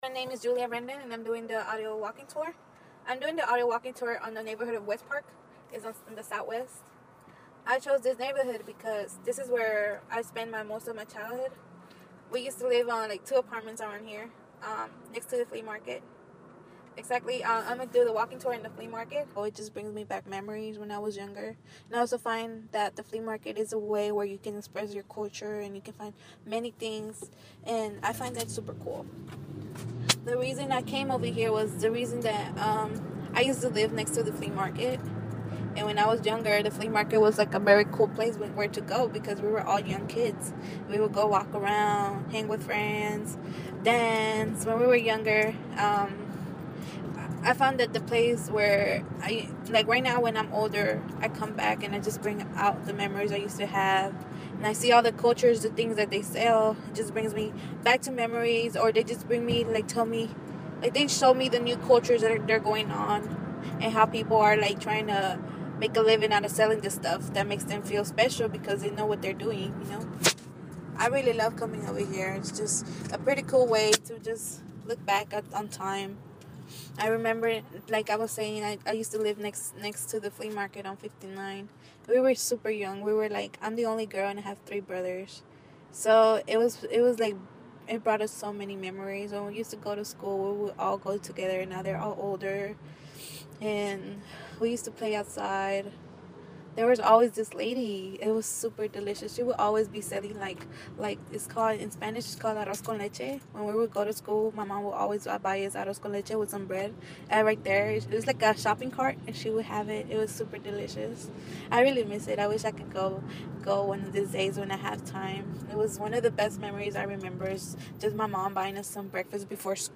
I took a walking tour on the neightboorhod of westpark, were i grew up and spend most of my childhood. Taking a walk in the flea market right next to the apartments were i used to live.